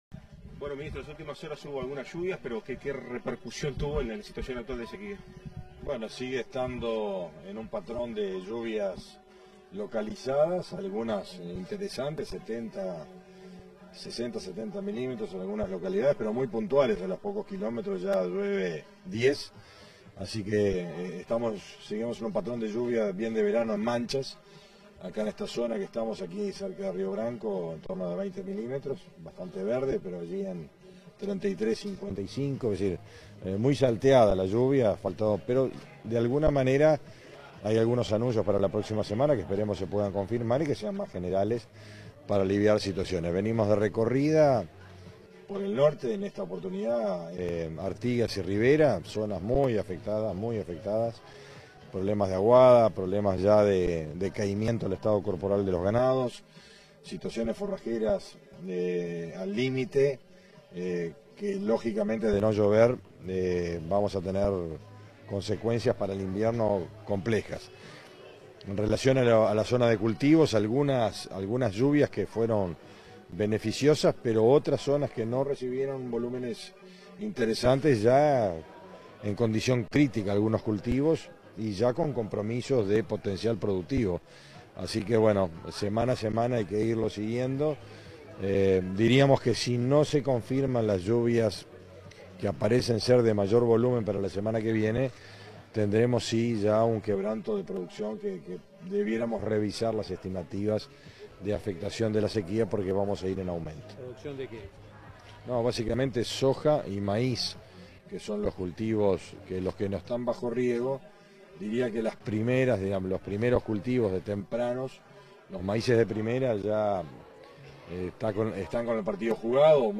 Declaraciones del ministro de Ganadería, Fernando Mattos, en Treinta y Tres
Declaraciones del ministro de Ganadería, Fernando Mattos, en Treinta y Tres 09/02/2023 Compartir Facebook X Copiar enlace WhatsApp LinkedIn Este jueves 9, el ministro de Ganandería Agricultura y Pesca, Fernando Mattos, dialogó con la prensa en el marco de la recorrida que realiza por el departamento de Treinta y Tres.